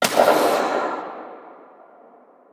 JackHammer_far_03.wav